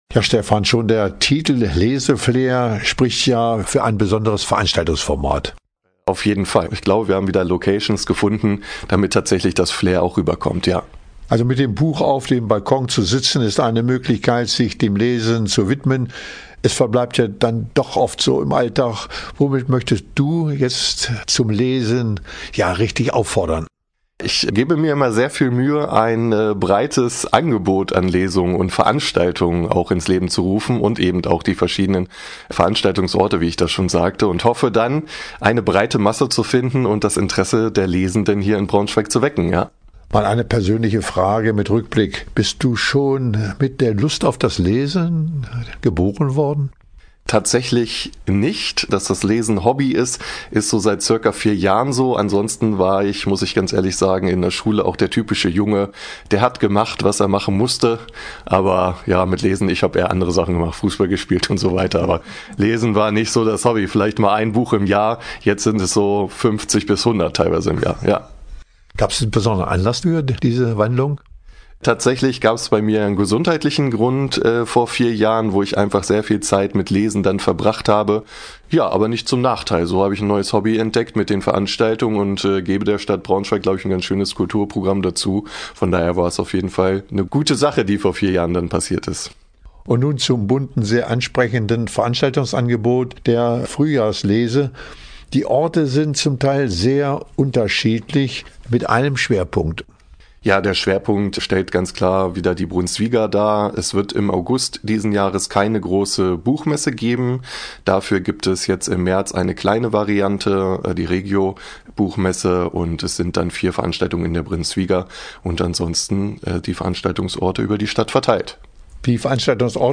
Interview-Leseflair-2026.mp3